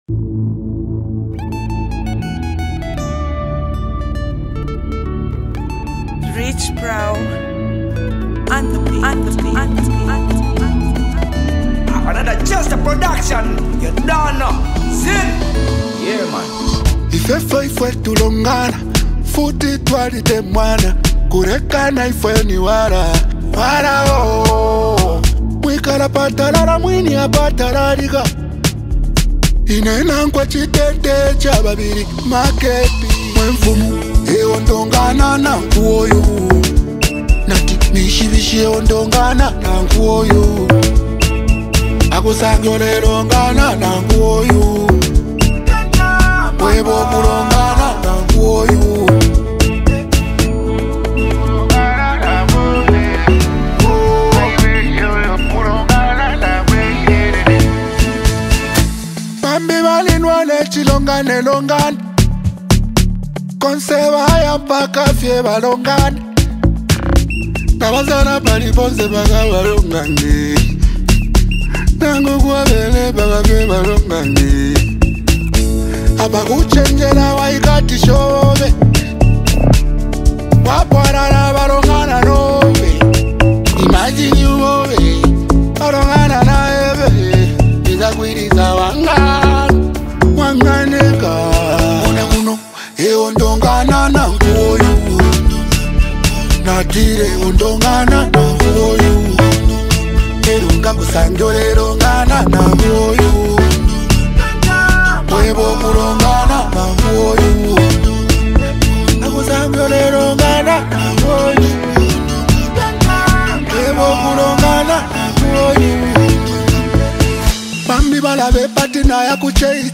catchy rhythms and heartfelt lyrics
The contemporary Afrobeat sound
soulful voice